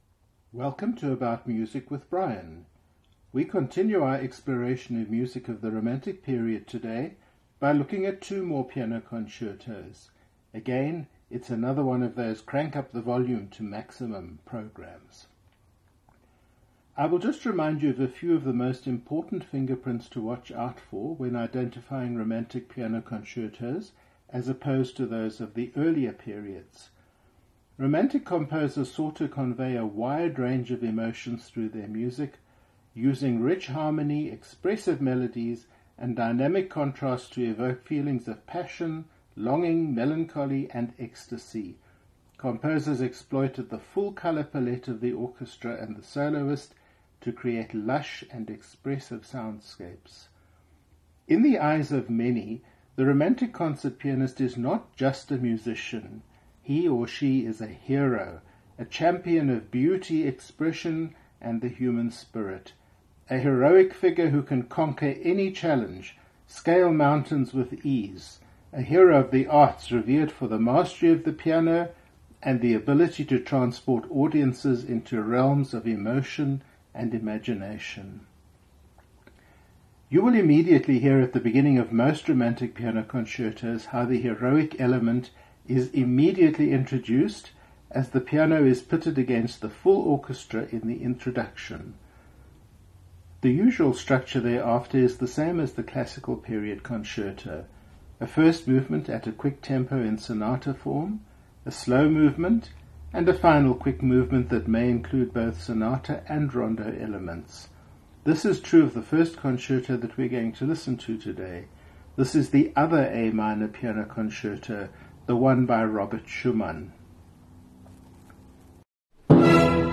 There are four big Piano Concertos from the Romantic period, which feature in almost every competition, played usually by multiple competitors.
Robert Schumann: Piano Concerto in A Minor Opus 54 Performed by Krystian Zimerman and the Berliner Philharmoniker conducted by Herbert von Karajan
Franz Liszt: Etudes de Concert S.144, number 3 in D flat “Un Sospiro” Performed by Claudio Arrau · Franz Liszt: Piano Concerto no 2 in A major S125 Performed by Stephen Hough and the Bergen Philharmonic Orchestra conducted by Andrew Litton